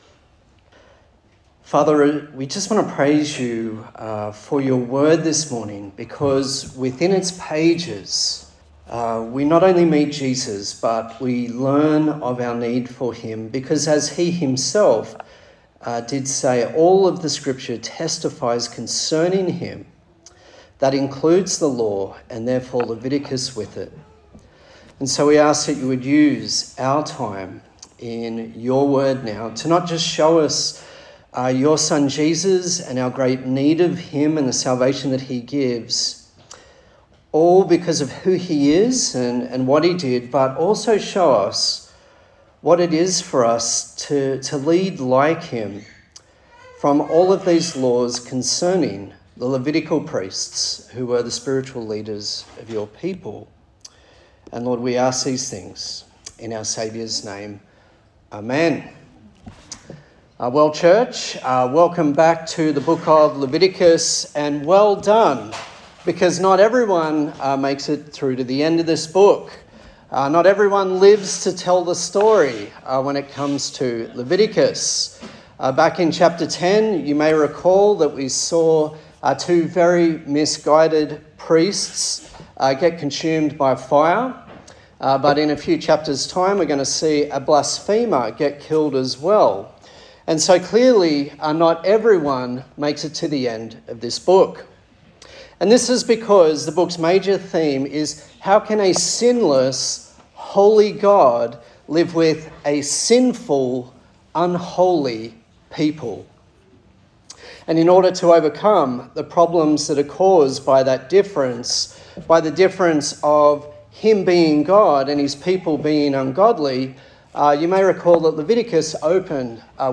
A sermon in the series on the book of Leviticus
Leviticus Passage: Leviticus 21:1-22:33 Service Type: Morning Service